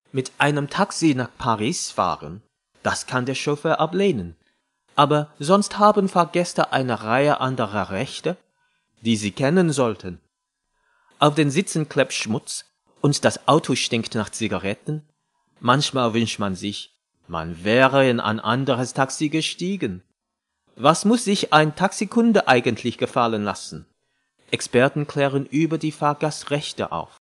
Chinese, Cantonese. Actor, singer, versatile, informative, experienced.
Study Choice Commercial (Cantonese)